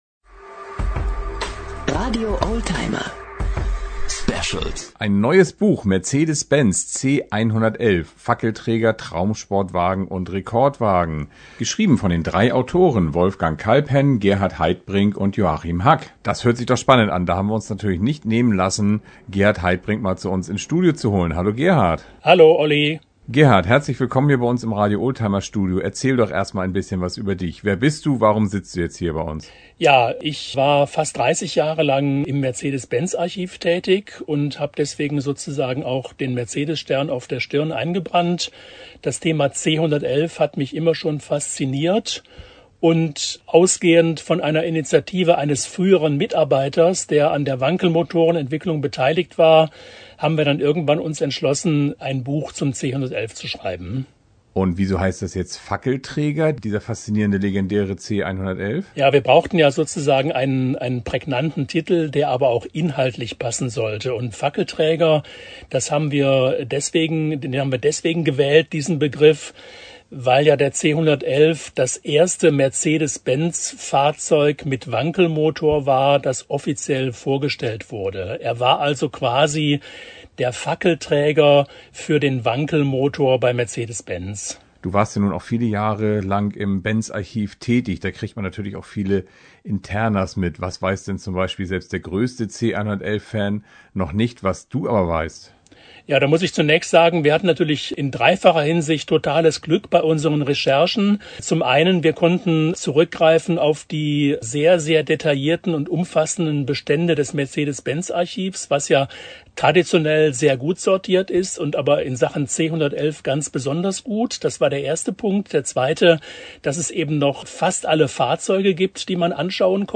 Und warum wurde der Wagen nie in Serie gefertigt? Hört und staunt hier im Radio-Oldtimer-Interview-Podcast, klick!